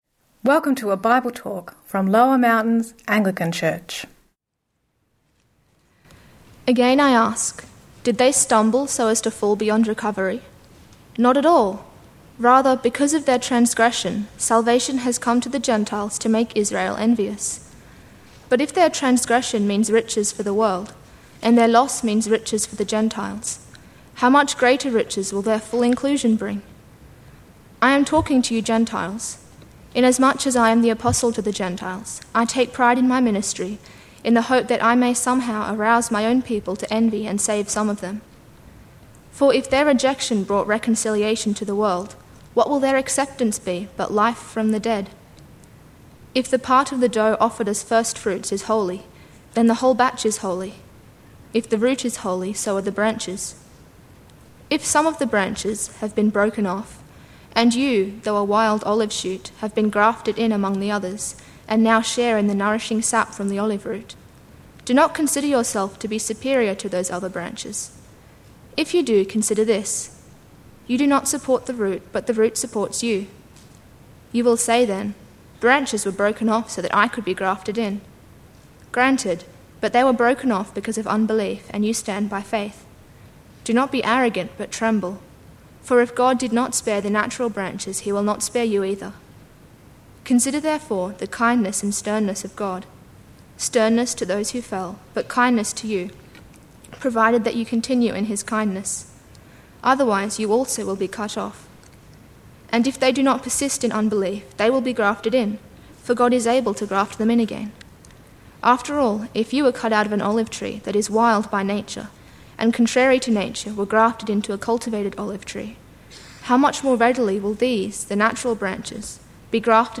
Sermon – Theological Gardening (Rom 11:11-36)